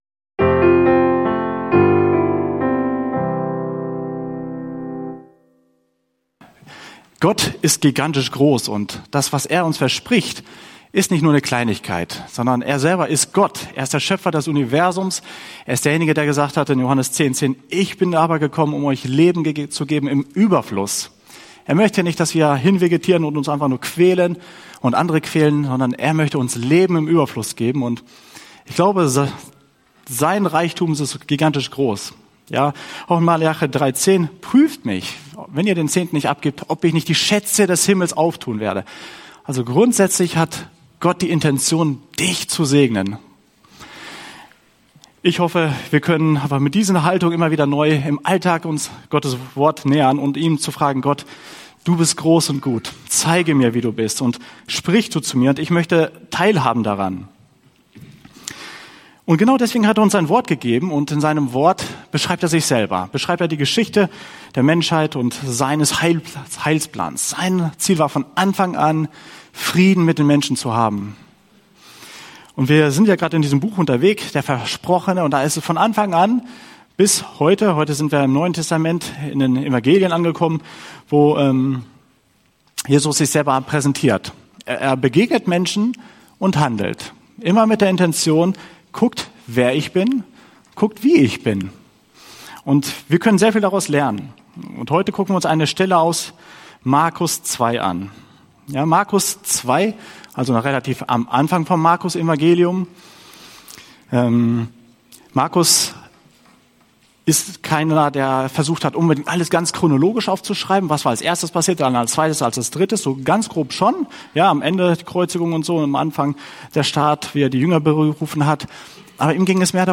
Höre inspirierende Predigten und lerne Jesus besser kennen.